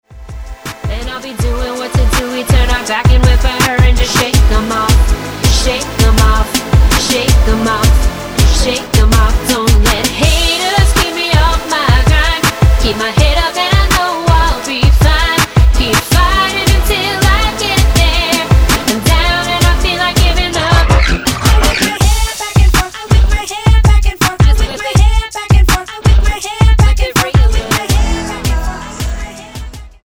NOTE: Vocal Tracks 10 Thru 18